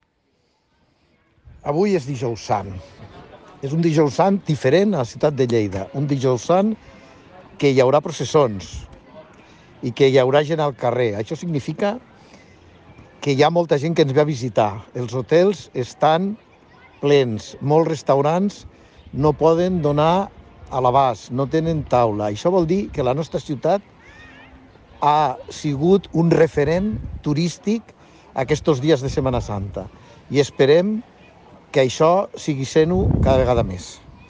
tall-de-veu-del-president-de-turisme-de-lleida-paco-cerda-sobre-les-previsions-de-visitants-durant-la-setmana-santa